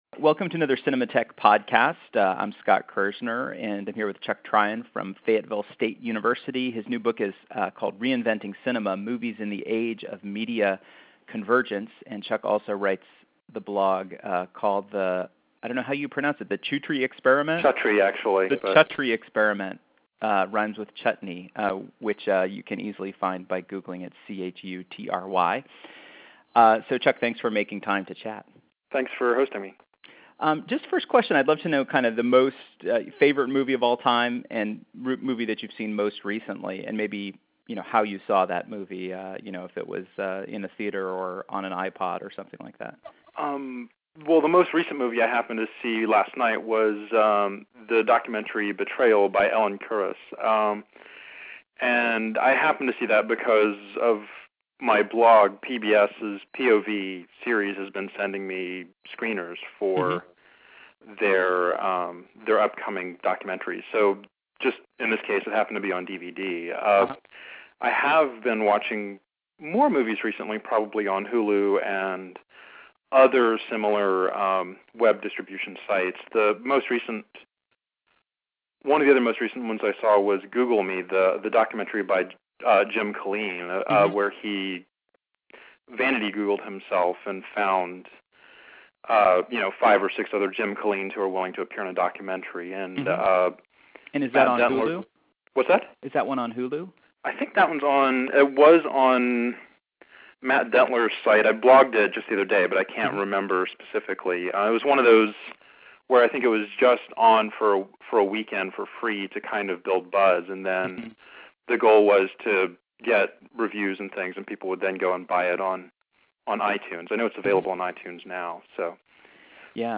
We caught up by phone yesterday for a conversation about some of the ways that new technologies are changing cinema. Among the topics we covered: - The shift from print to digital movie criticism - Will studios remain power players in the future?